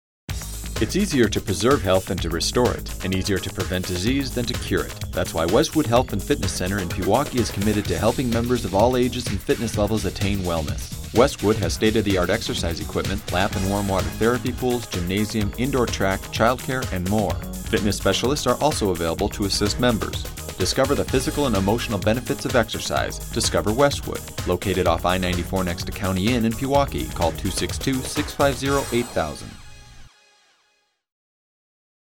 Westwood Health and Fitness Center Radio Commercial